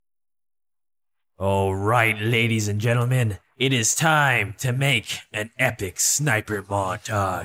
Sniper Montage No Music